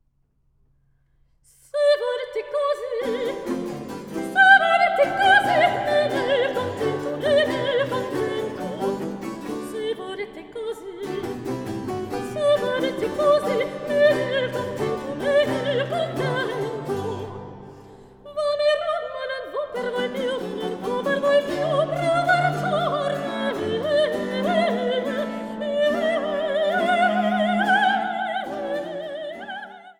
Sopran
Cembalo
Violoncello
Théorbe